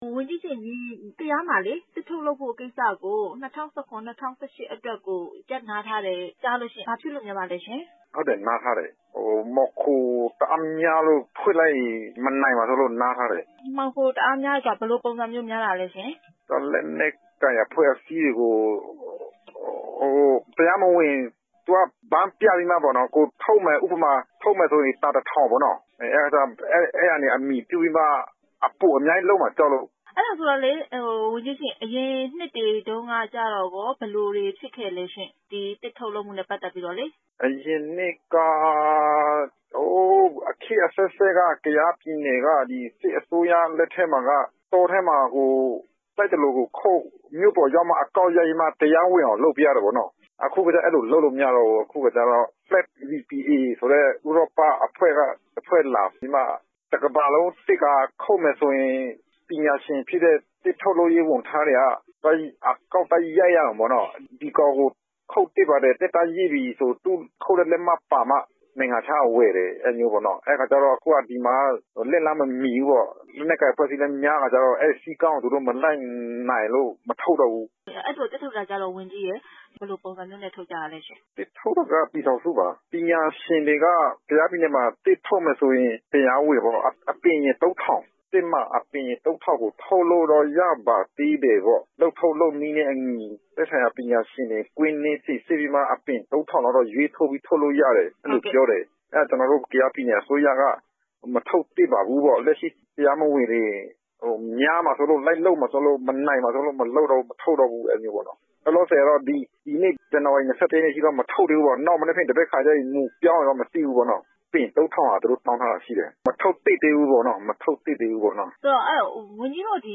ကယားပြည်နယ်မှာ သစ်ထုတ်ခွင့် ရပ်ဆိုင်းတဲ့အကြောင်း ဆက်သွယ်မေးမြန်းချက်